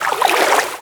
water3.wav